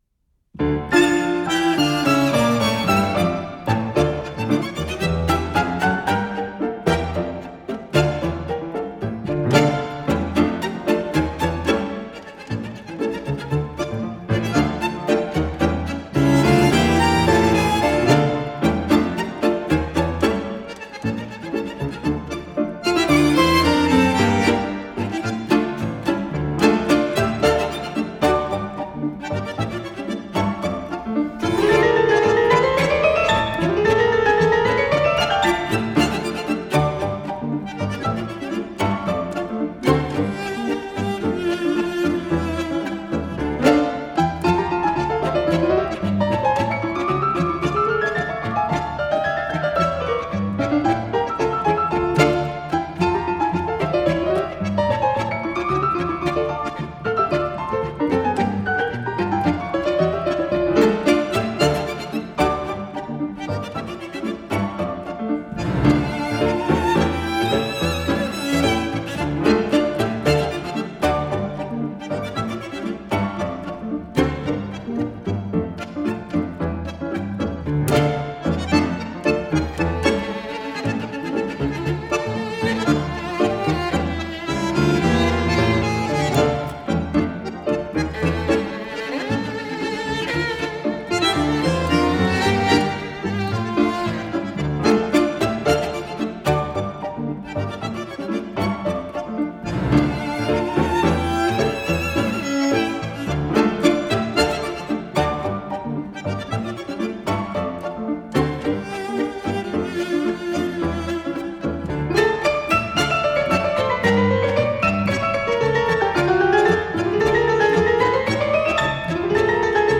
chamber-sized ensemble.
Genre: Tango